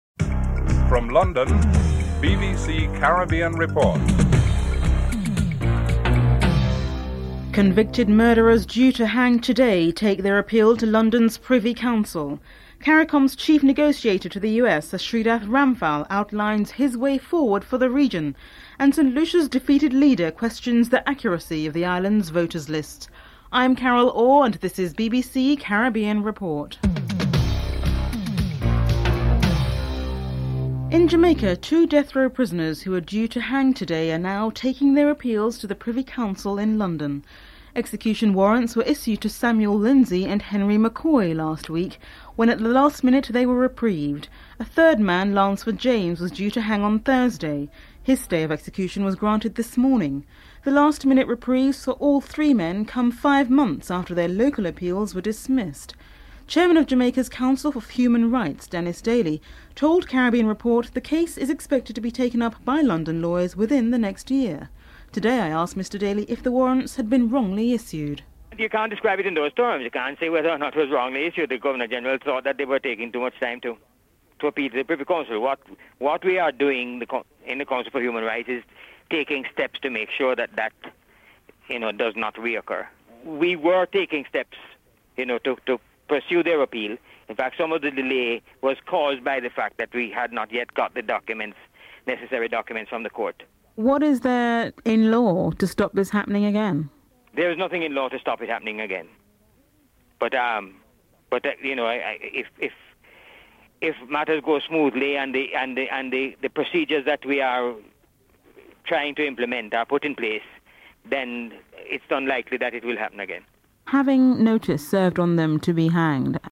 1. Headlines (00:00:00:29)
Kofi Annan, UN Secretary General and Edwin Carrington, Caricom's Secretary General are interviewed (02:12-04:21)